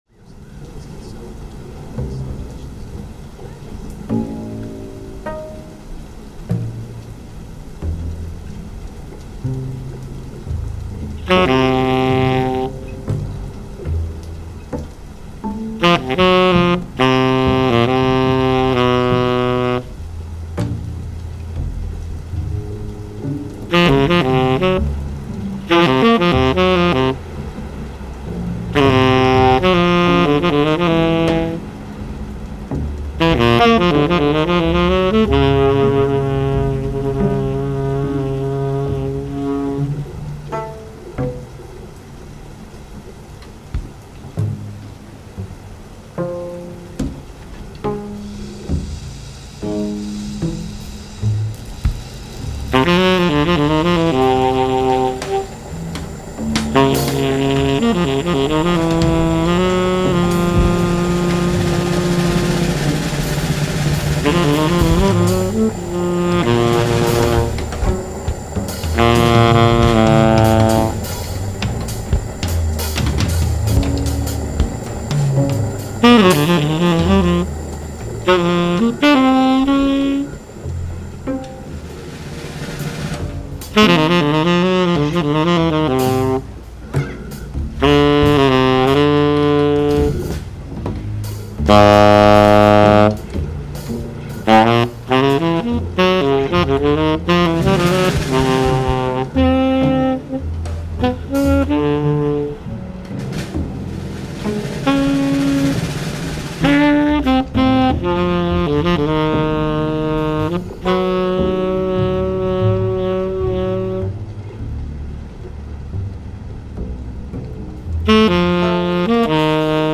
live streamed
Live Music, Saxophone
(Live mashed) sonic fictions